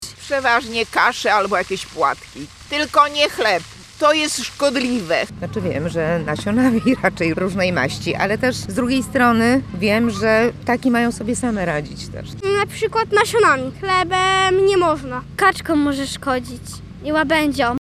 labedzie-sonda.mp3